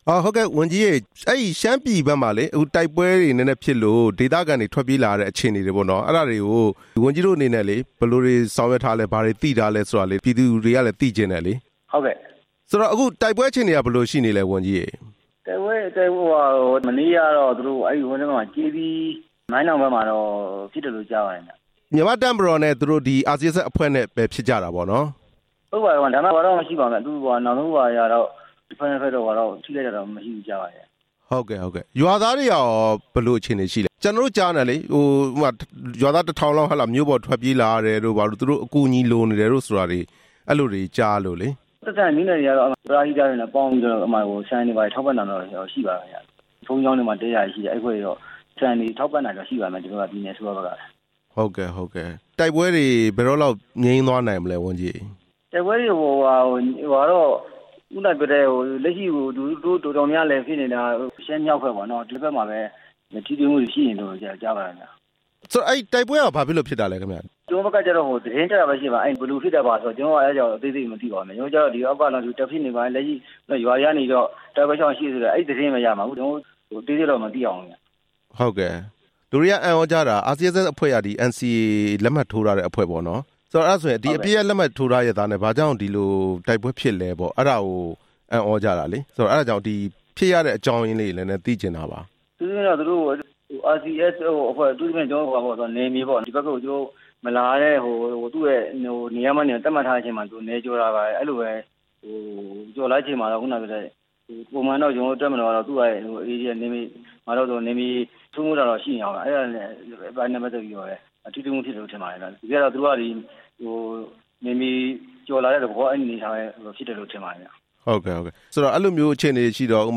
ရှမ်းမြောက်တိုက်ပွဲတွေအကြောင်း လုံ/နယ်ဝန်ကြီးနဲ့မေးမြန်းချက်